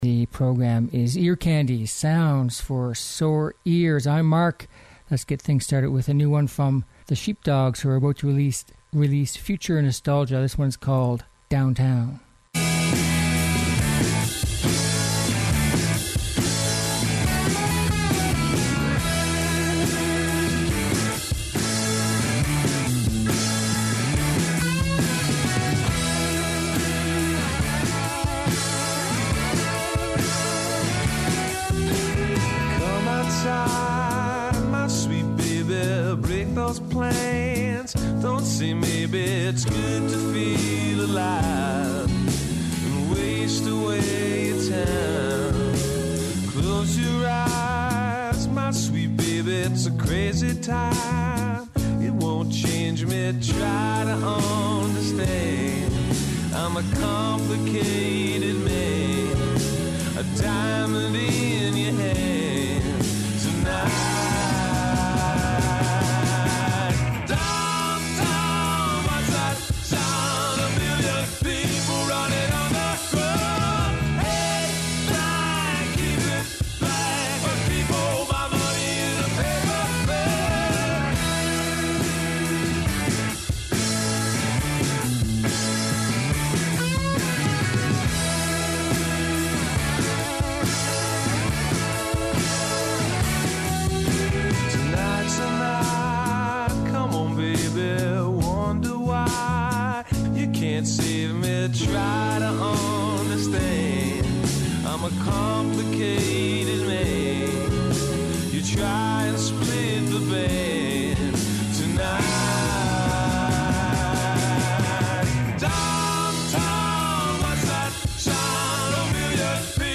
Catchy uptempo pop and rock songs